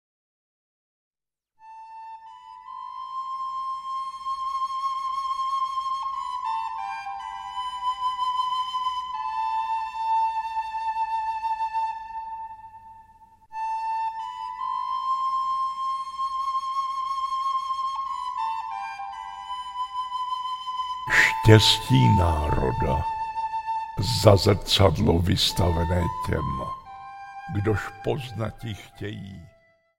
Štěstí národa audiokniha
Ukázka z knihy